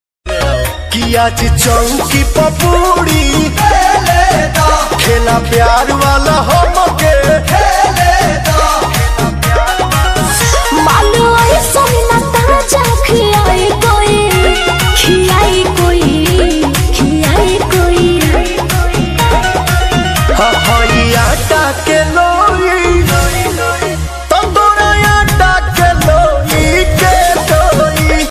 bhojpuri ringtone 2023